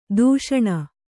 ♪ dūṣaṇa